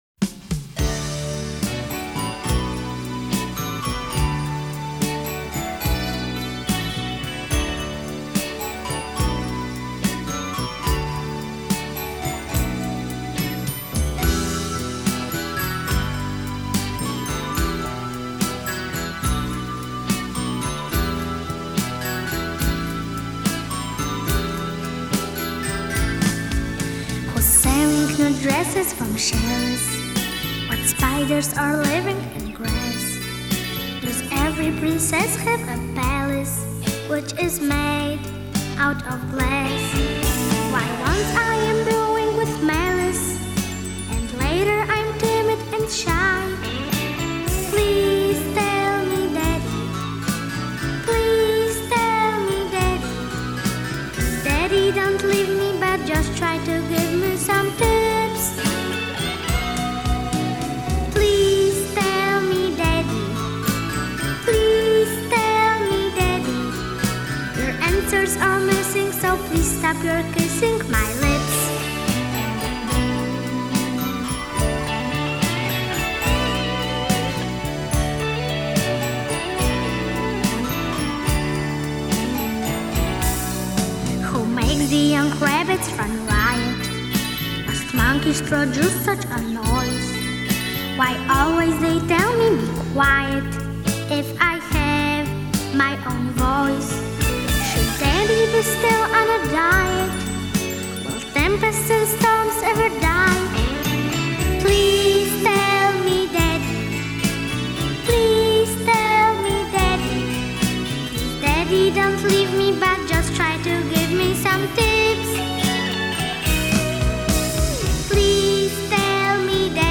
New Britain, CT, 2007